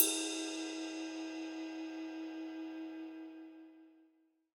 • Ride Single Shot E Key 01.wav
Royality free ride cymbal one shot tuned to the E note. Loudest frequency: 6706Hz
ride-single-shot-e-key-01-OCC.wav